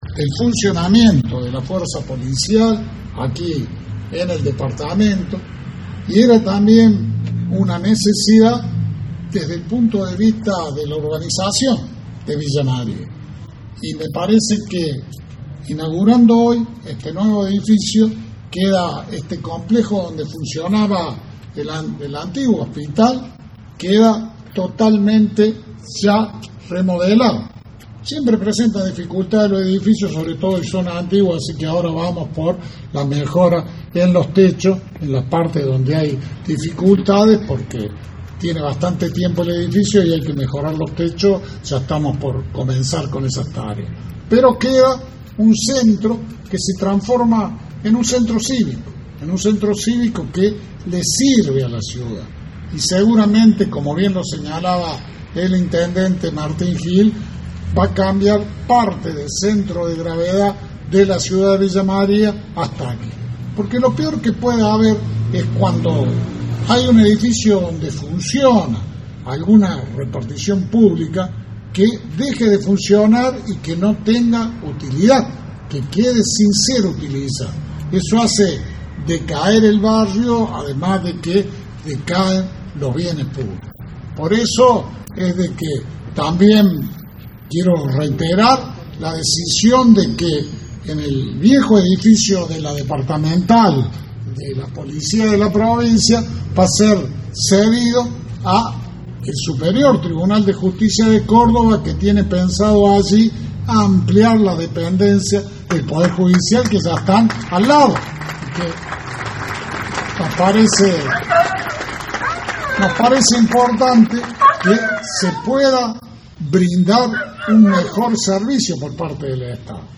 AUDIO – GOBERNADOR SCHIARETTI
Las autoridades recorrieron el edificio recientemente refuncionalizado y brindaron sus palabras frente a quienes acompañaron la especial ocasión.